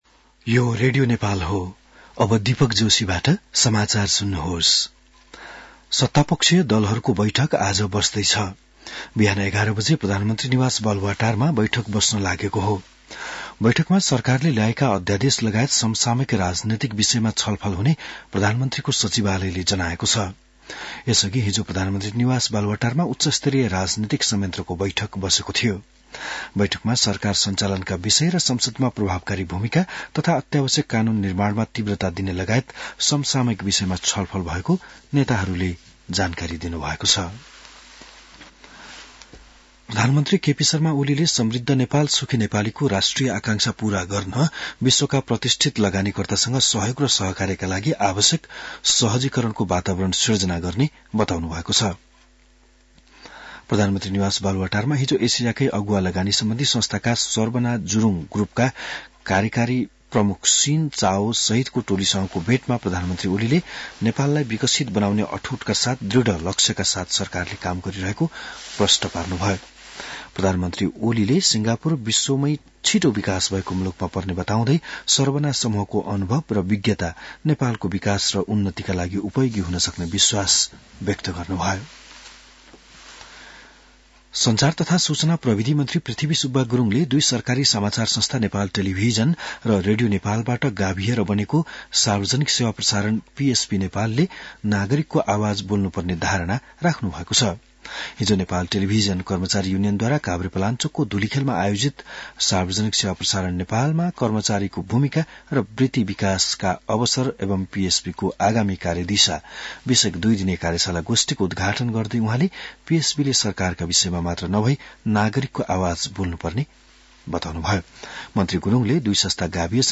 बिहान १० बजेको नेपाली समाचार : ४ फागुन , २०८१